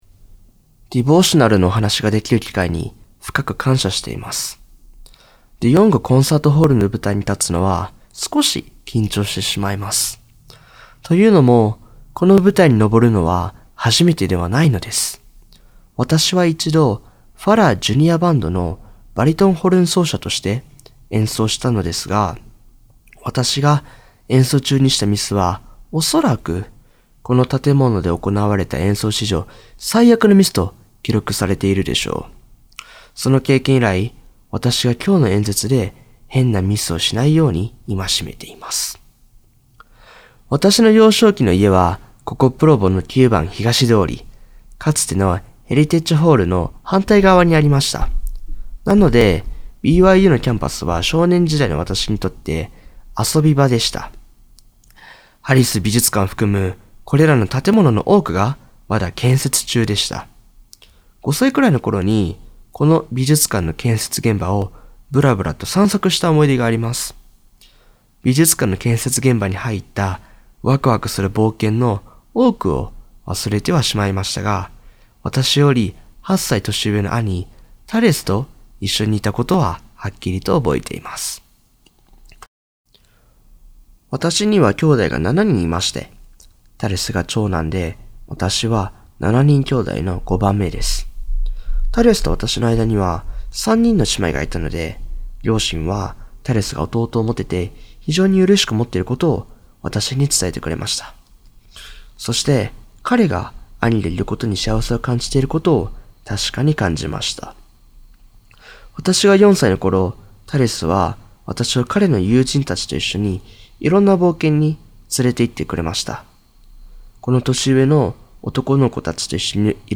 ディボーショナル